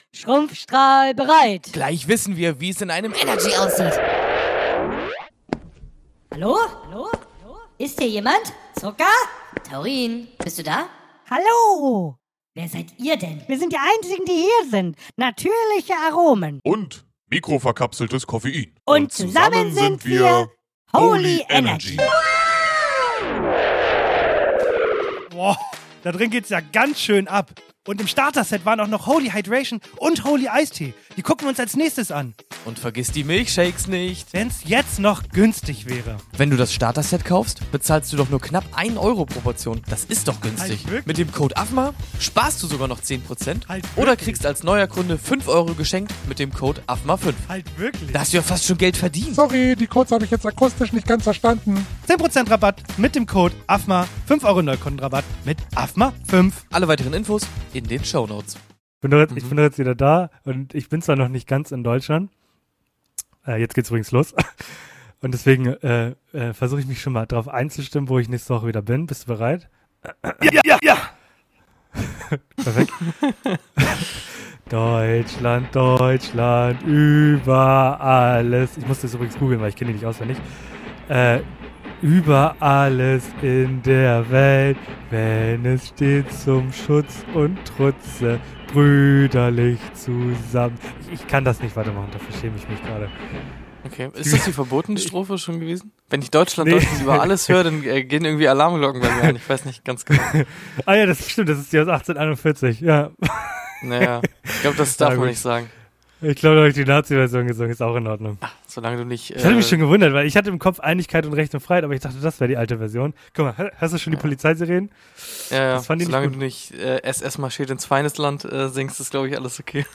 die Verbindung lässt allerdings immer noch zu wünschen übrig (Danke Hotel WLAN!).